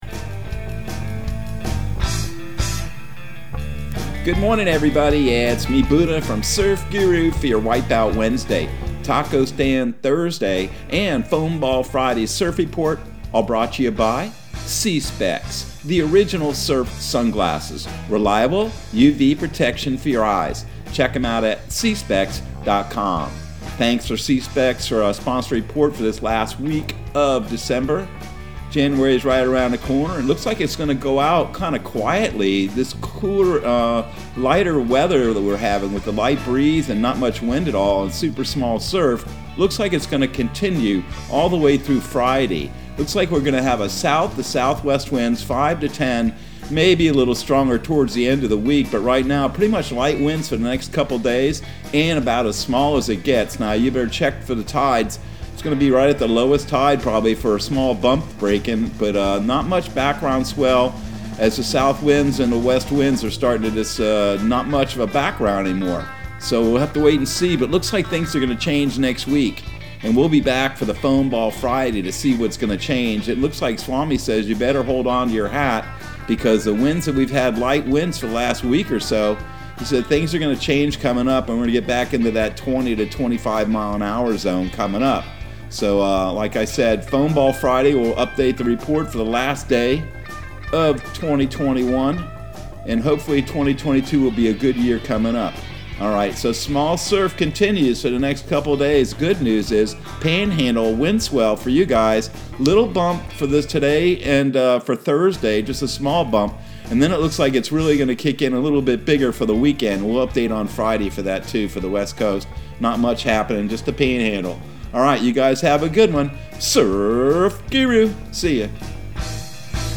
Surf Guru Surf Report and Forecast 12/29/2021 Audio surf report and surf forecast on December 29 for Central Florida and the Southeast.